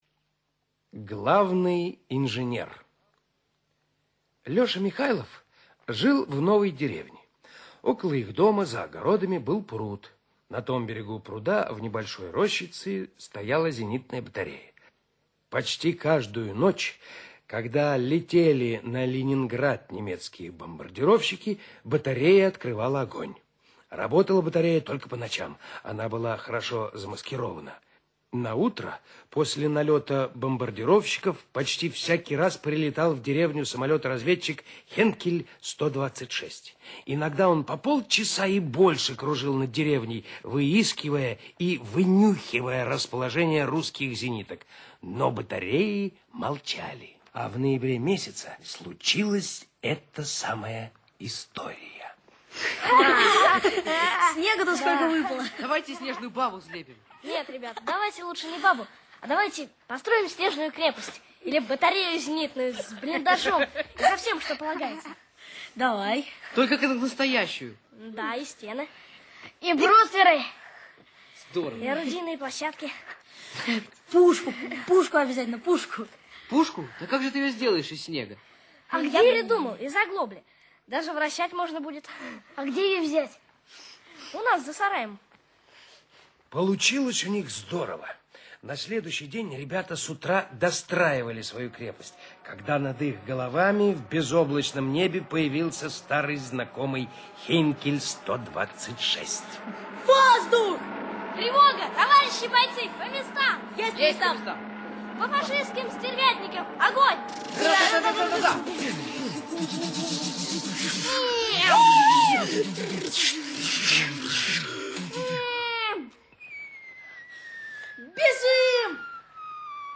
Аудиорассказ «Главный инженер»